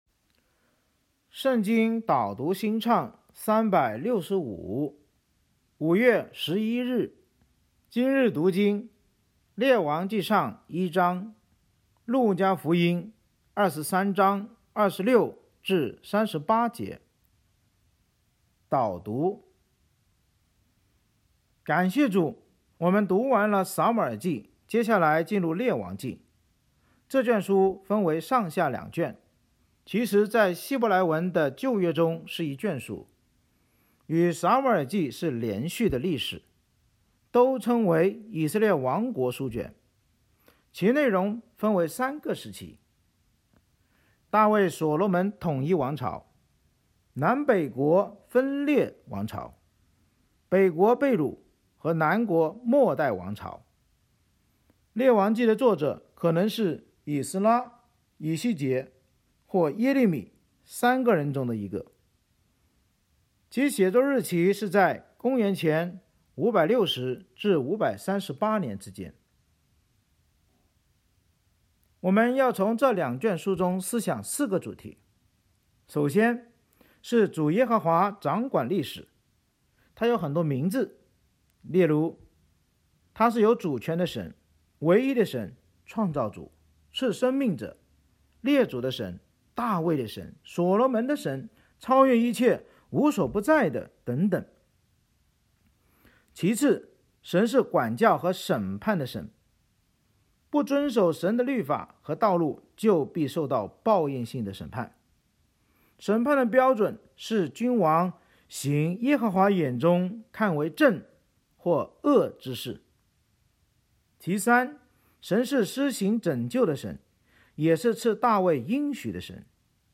圣经导读&经文朗读 – 05月11日（音频+文字+新歌）